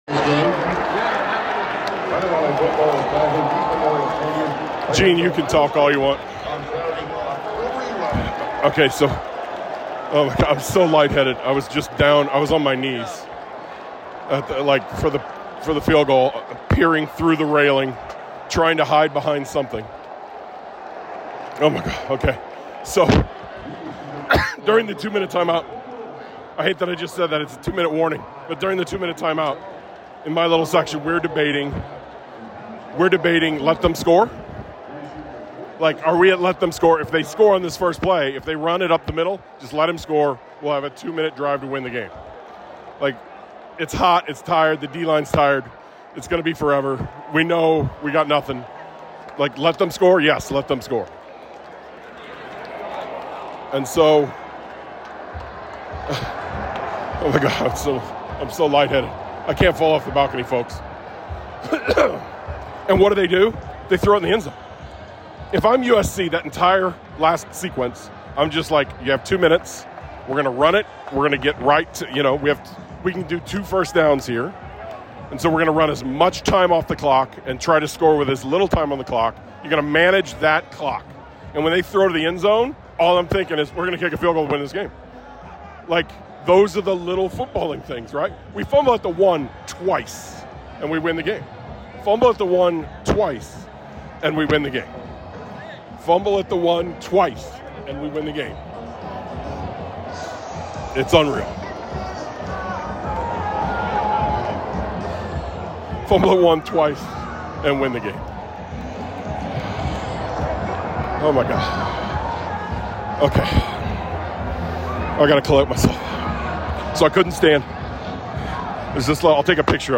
We Love No Other From The Stands - USC Play episode Sep 27, 2025 9 mins Bookmarks Episode Description It’s rather chaotic because I nearly passed out after the field goal went through but here’s ten minutes from the stands.